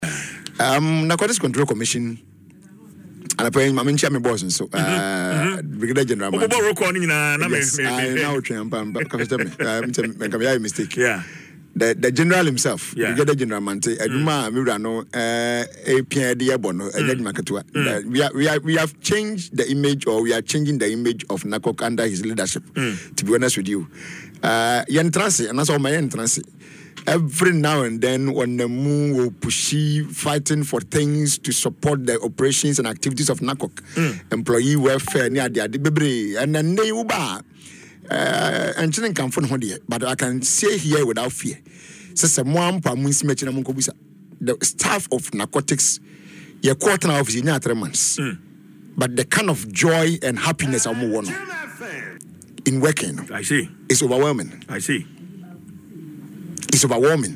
Speaking in an interview on Adom FM’s Dwaso Nsem, Twum Barima expressed confidence that the commission has achieved in a short time what was not accomplished in eight years under the previous Akufo-Addo administration.